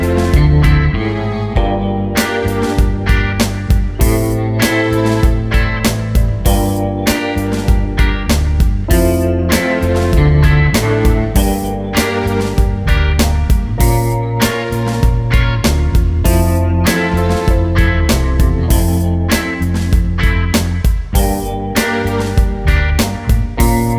no Rap or Backing Vocals Pop (2000s) 4:39 Buy £1.50